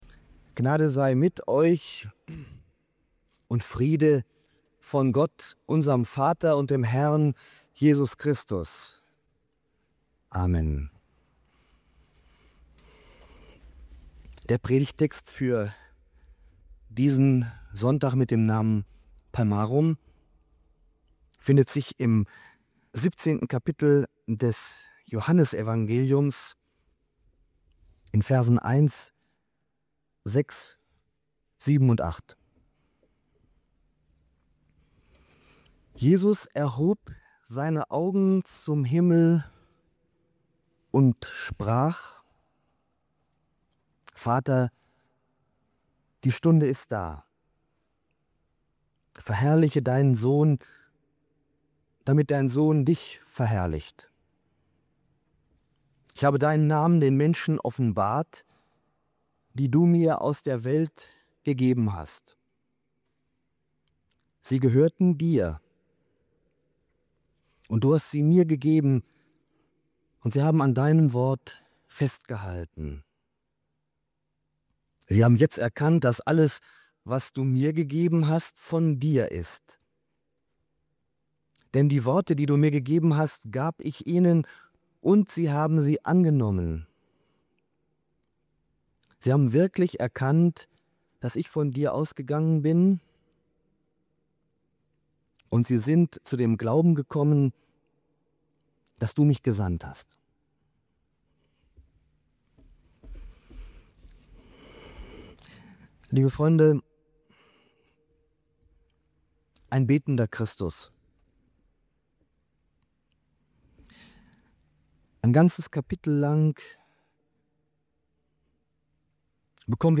Beschreibung vor 1 Tag In dieser Folge tauchen wir tief ein in das sogenannte „hohepriesterliche Gebet“ aus dem Johannesevangelium (Johannes 17). Die Predigt zum Sonntag Palmarum eröffnet einen seltenen, intimen Blick in das Gespräch zwischen Jesus und dem Vater – ein Gebet, das zugleich öffentlich gesprochen wird und bis in die Ewigkeit hineinreicht. Im Zentrum steht die Frage: Wie offenbart sich Gott dem Menschen?